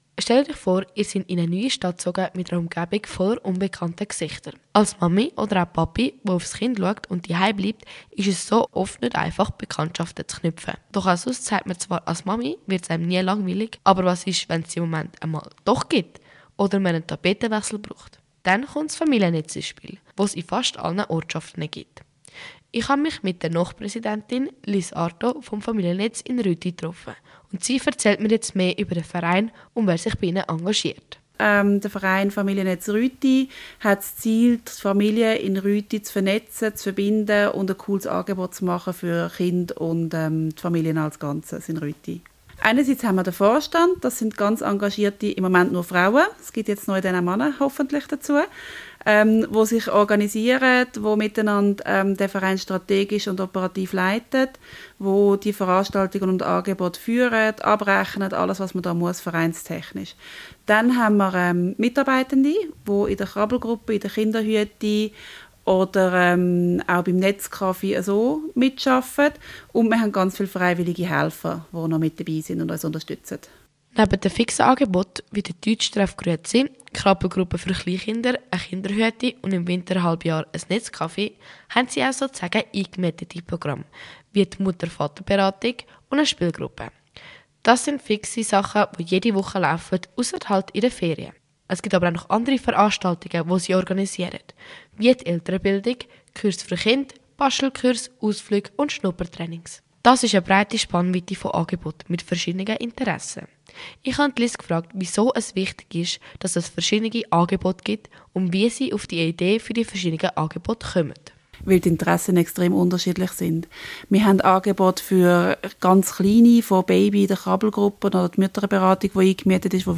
Radiobeitrag Familiennetz